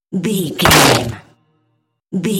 Dramatic hit bloody bone
Sound Effects
heavy
intense
dark
aggressive
hits